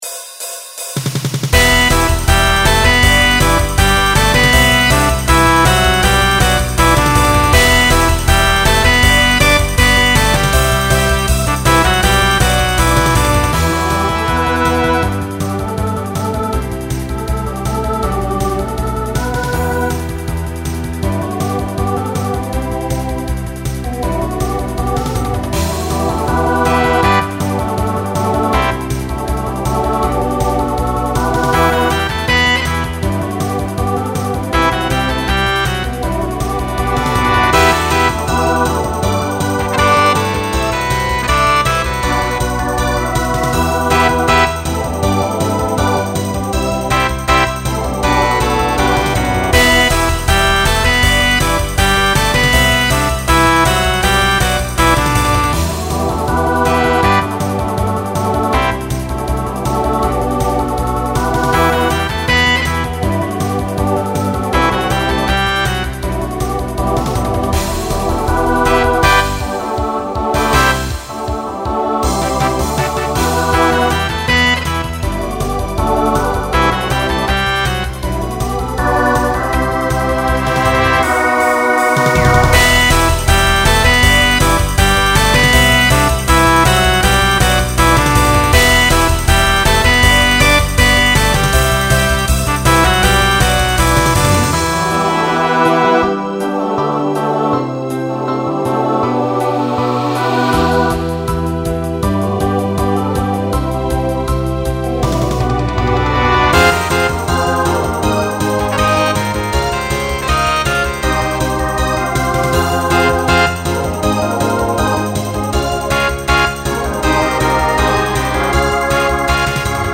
Genre Rock Instrumental combo
Opener Voicing SATB